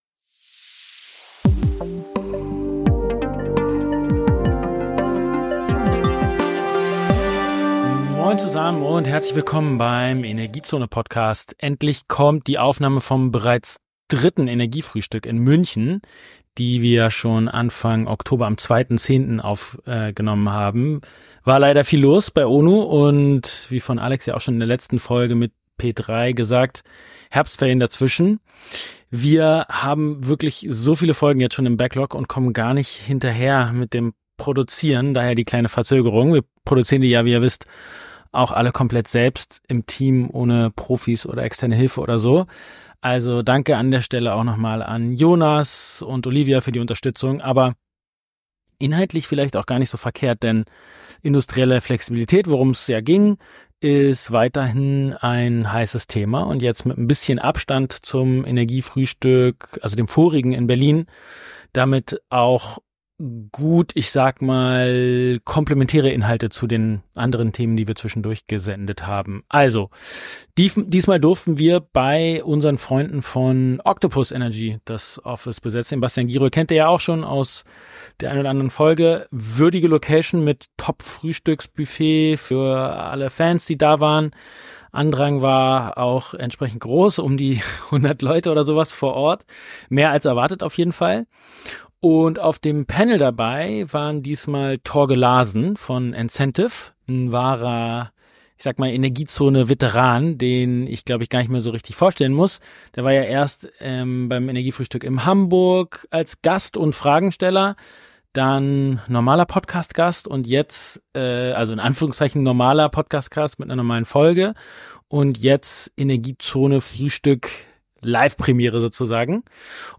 In dieser Folge des Energiezone-Podcasts gibt’s die Aufzeichnung unseres dritten Energiefrühstücks vom 2. Oktober in München mit Live Panel.